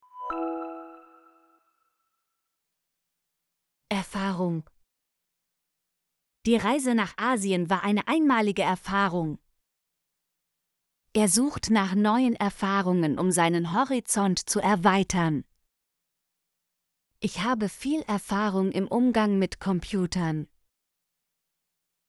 erfahrung - Example Sentences & Pronunciation, German Frequency List